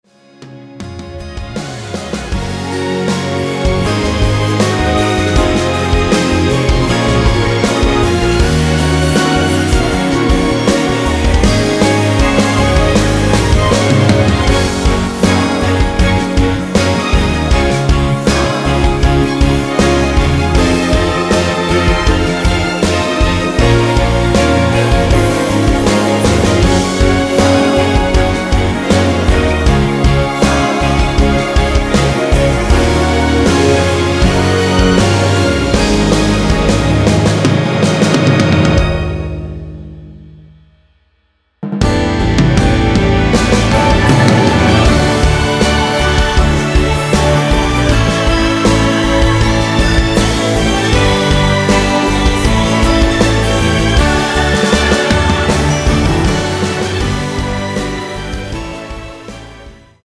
원키에서(+2)올린 코러스 포함된 MR입니다.
Db
앞부분30초, 뒷부분30초씩 편집해서 올려 드리고 있습니다.